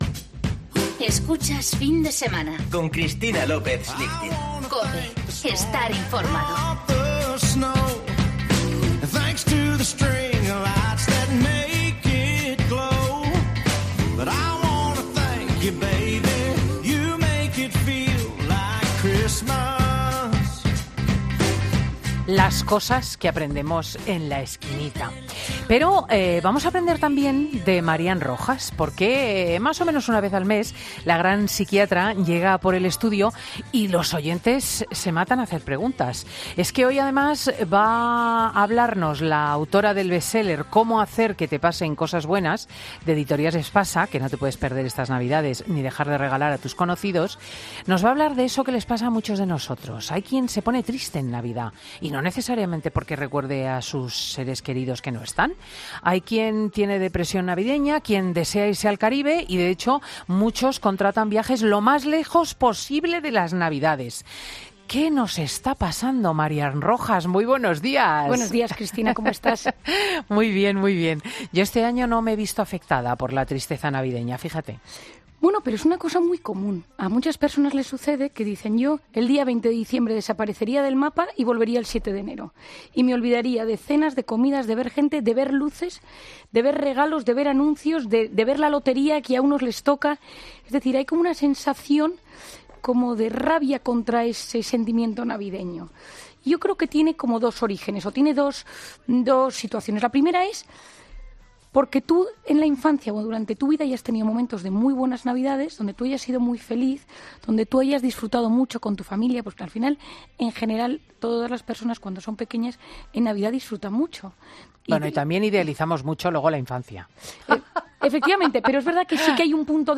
AUDIO: La psicóloga Marian Rojas nos cuenta sus claves para combatir la tristeza en Navidad